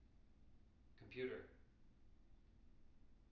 wake-word
tng-computer-323.wav